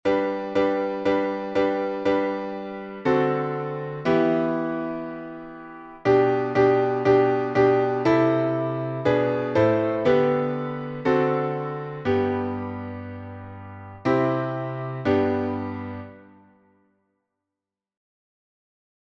Words from I Chronicles 29:14 Tune by Ludwig van Beethoven Key signature: G major (1 sharp)